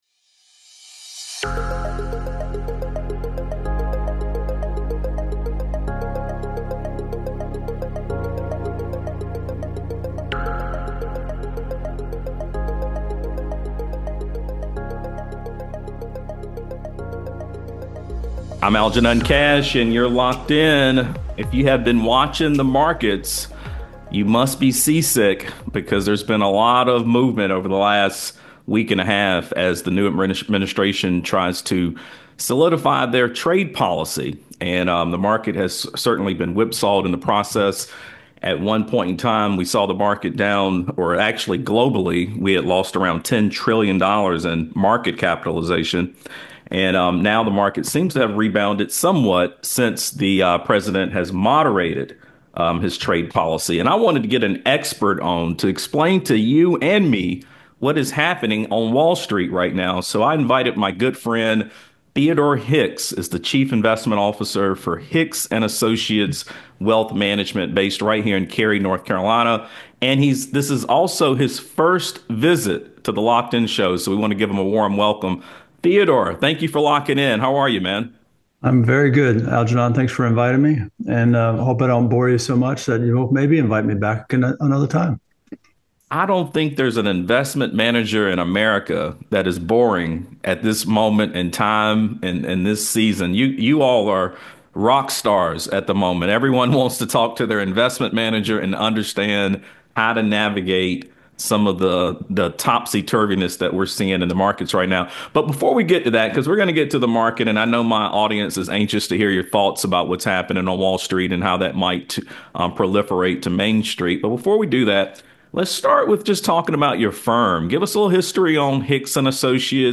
Money Moves & Market Shifts: A Conversation